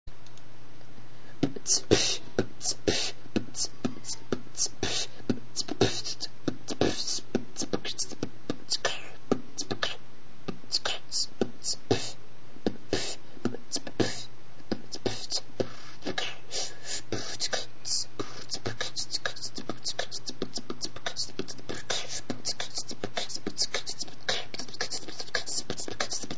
Сидел, тихо битил и тут в голову пришел как мне показалось интересный бит.
Сори что в конце резко обрывается, микро отключился